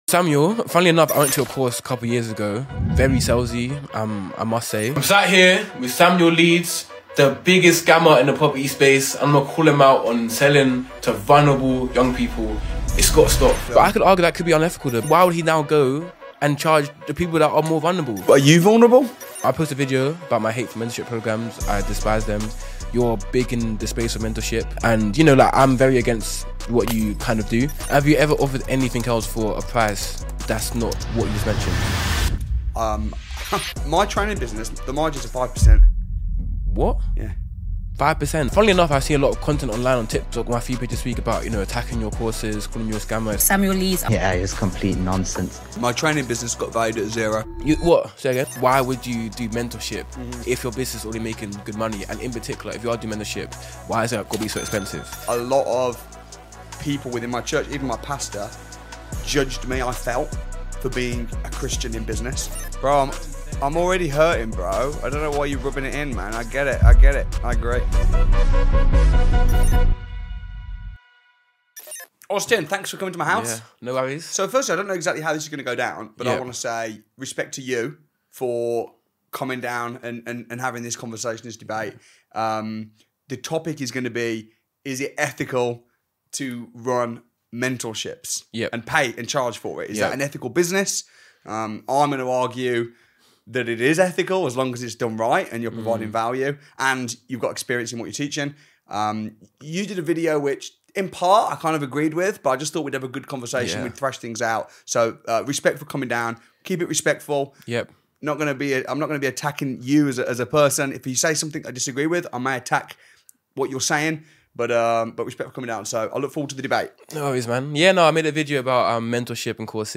Rather than dodge criticism, I invited him to my home for a face-to-face debate. Was it awkward? Yes.